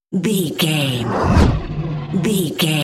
Horror whoosh creature
Sound Effects
Atonal
scary
ominous
eerie
whoosh